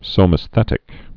(sōmĕs-thĕtĭk)